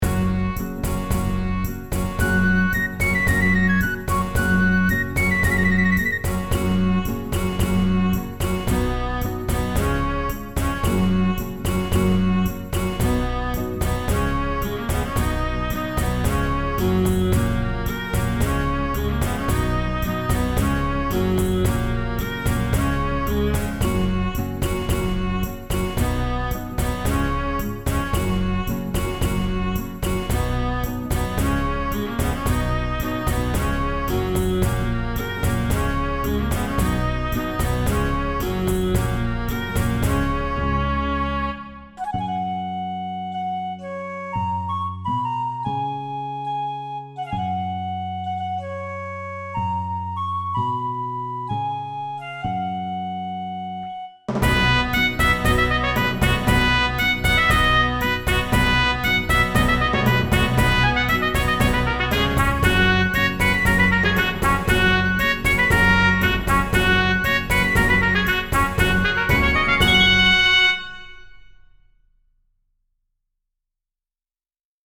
karaoke verziója